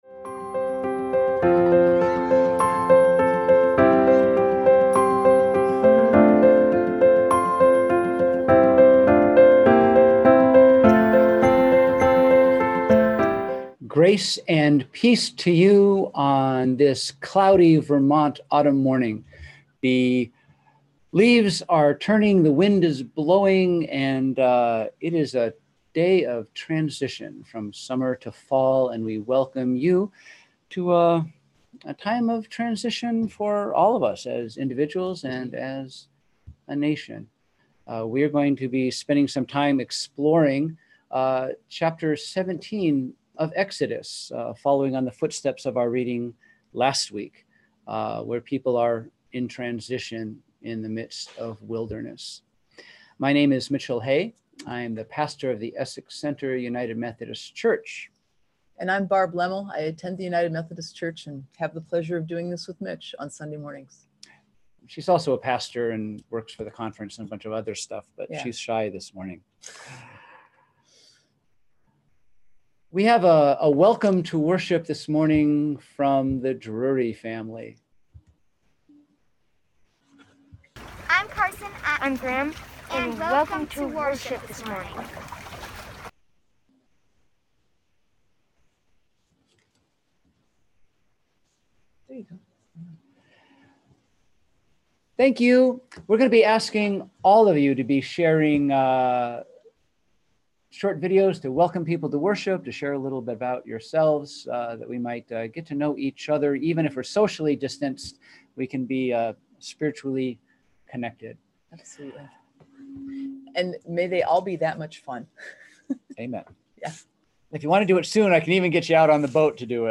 We held virtual worship on Sunday, September 27, 2020 at 10am!